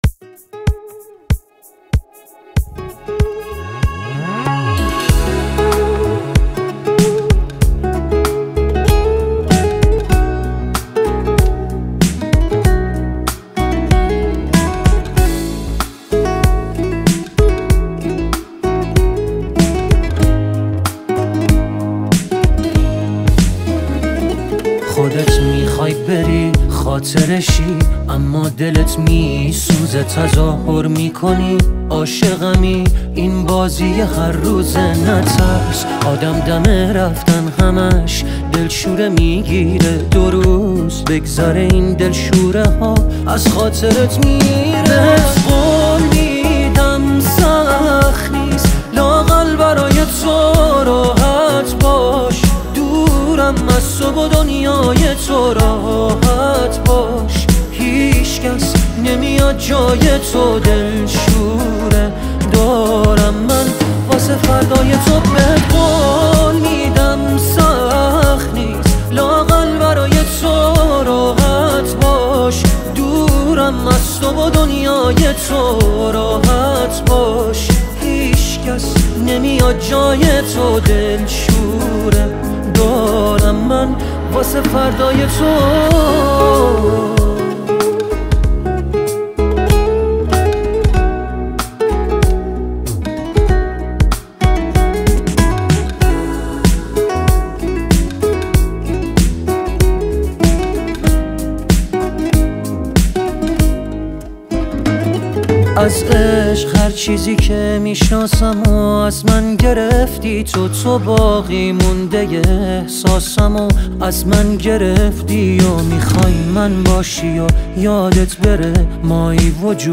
عاشقانه
غمگین